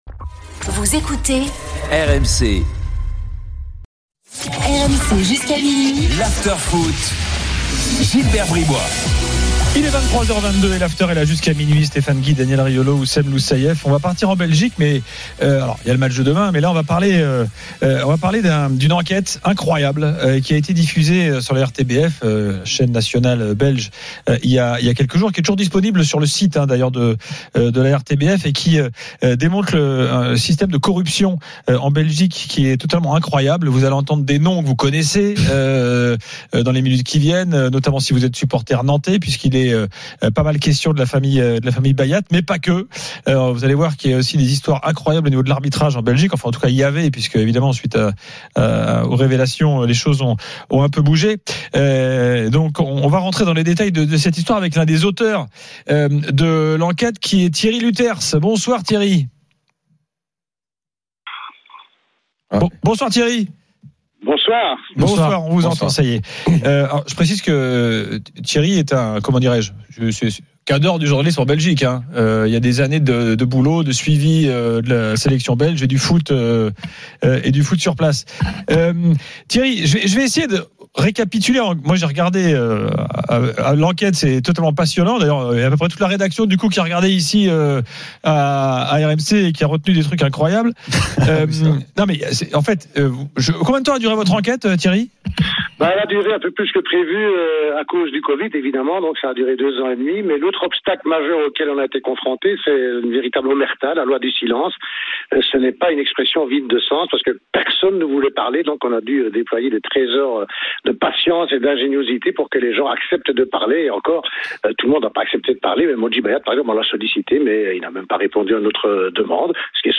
Chaque jour, écoutez le Best-of de l'Afterfoot, sur RMC la radio du Sport !
RMC est une radio généraliste, essentiellement axée sur l'actualité et sur l'interactivité avec les auditeurs, dans un format 100% parlé, inédit en France.